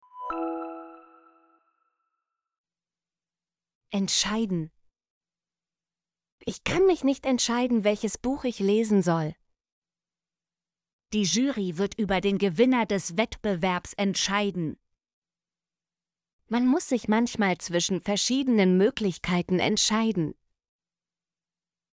entscheiden - Example Sentences & Pronunciation, German Frequency List